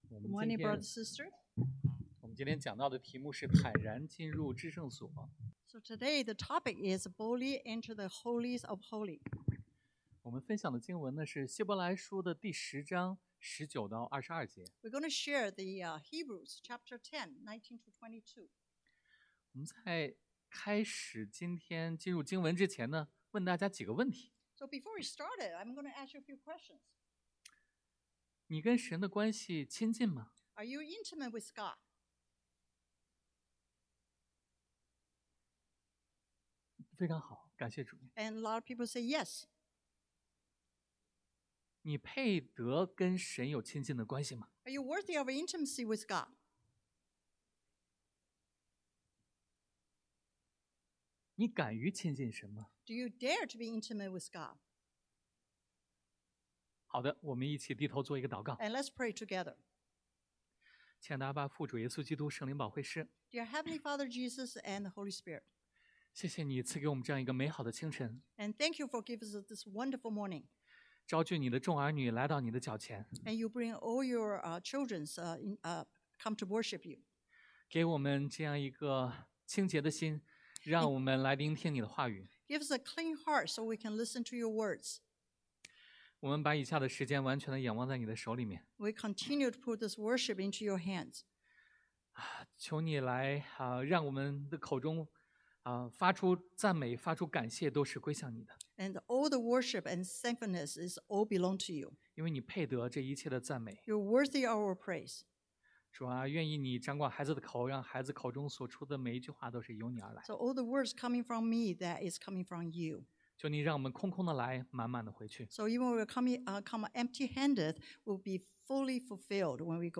Passage: 希伯来书 Hebrews 10:19-22 Service Type: Sunday AM The New Access 新的通道 The New Priest 新的祭司 The New Attitude 新的心态 « 2025-06-01 What Do You Have?